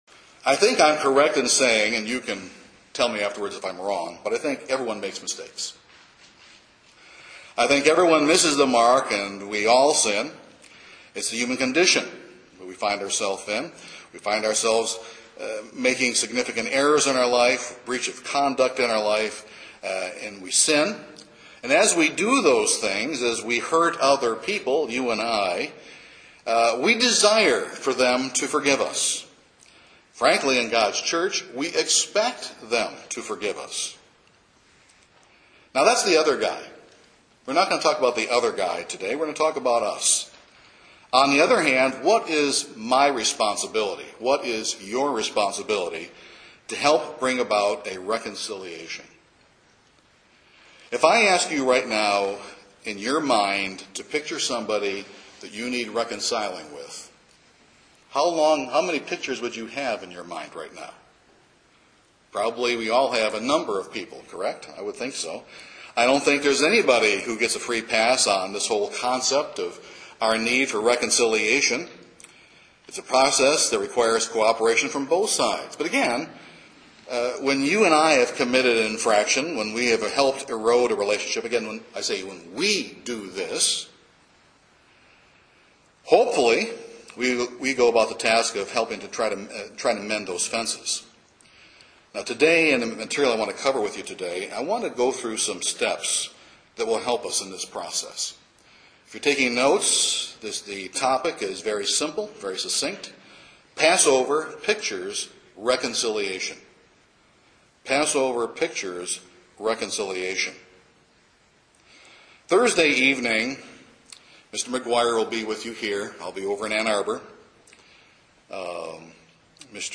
However, Passover also pictures that we are to be reconciled to one another and that we have been given the ministry of reconciliation. This sermon takes a closer look at our responsibilities in these regards.